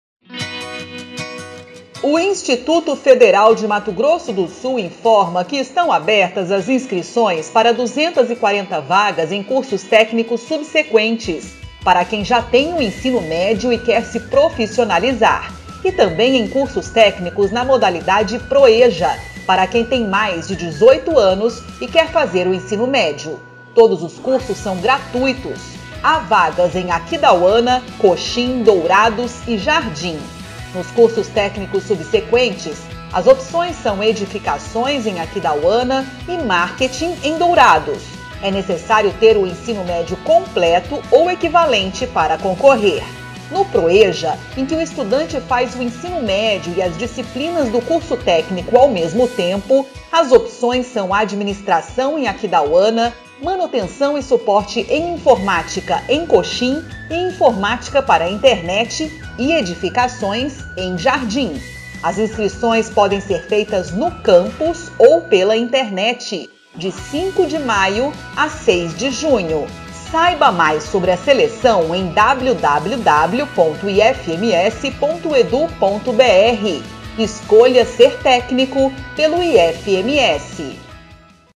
Áudio enviado às rádios para divulgação institucional do IFMS.